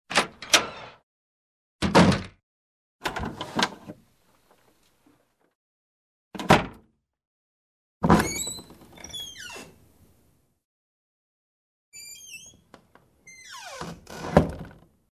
Звуки хлопанья дверью
Деревянная дверь открывание и закрывание